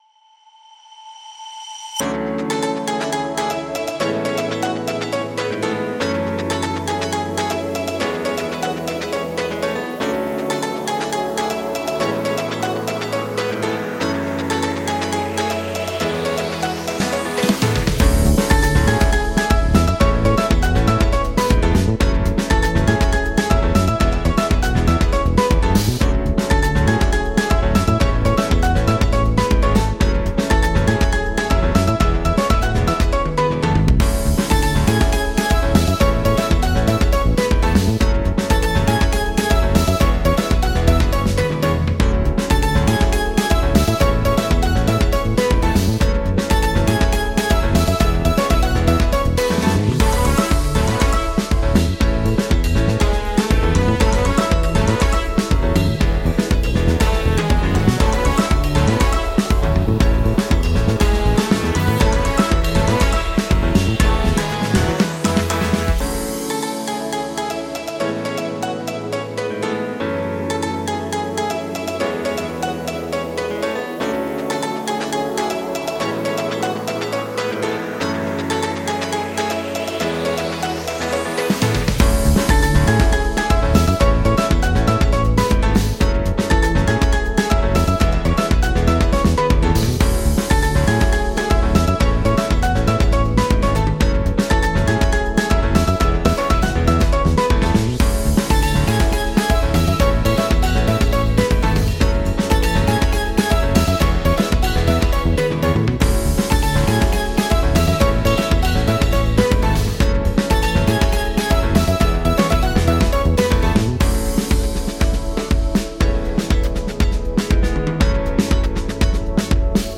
Всем привет.Подскажите как сделать планы в этом треке?Чтоб микс был прозрачный?У меня на слух каша получается.